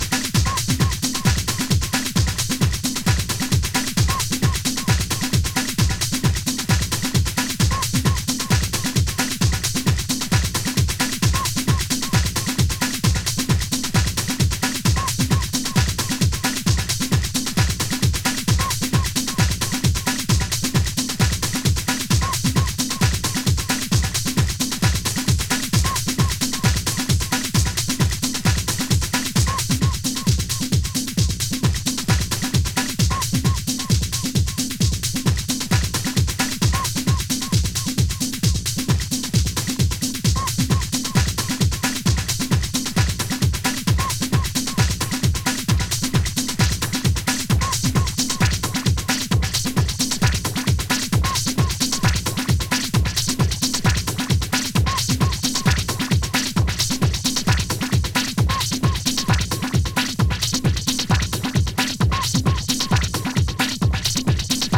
荒々しい質感が堪らないTechno！